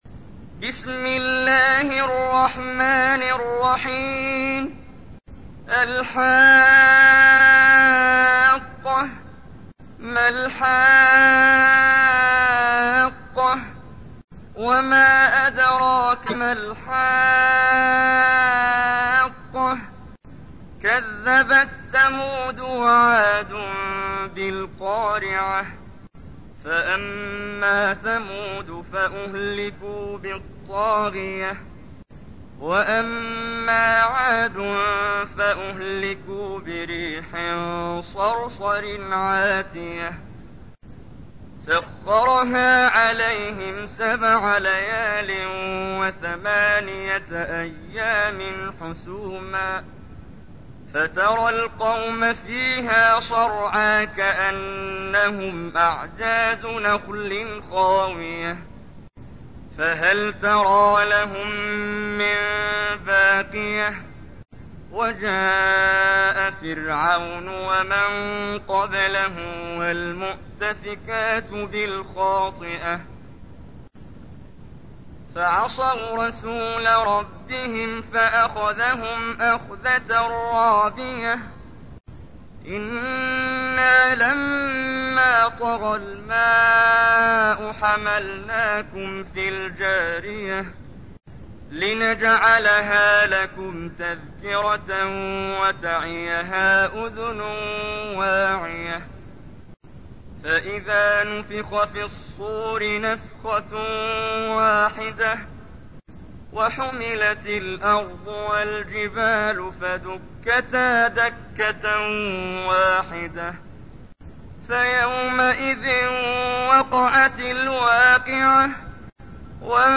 تحميل : 69. سورة الحاقة / القارئ محمد جبريل / القرآن الكريم / موقع يا حسين